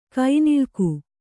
♪ kainiḷku